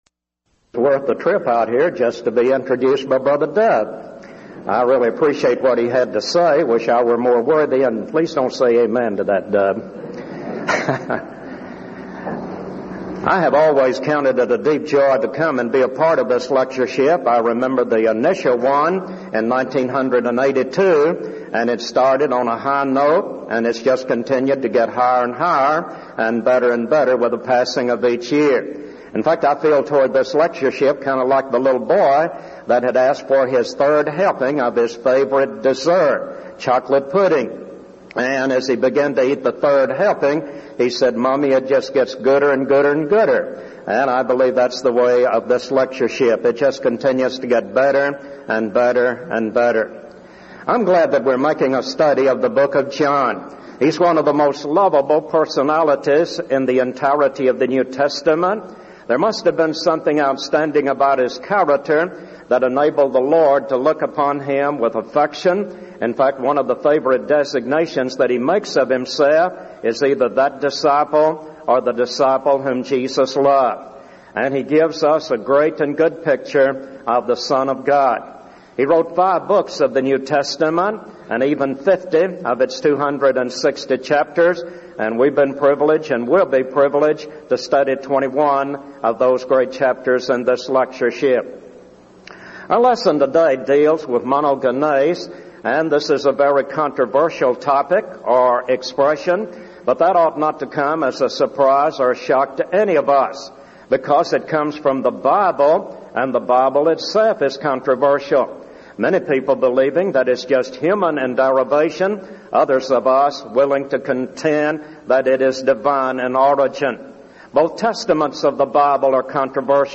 Event: 1999 Denton Lectures
lecture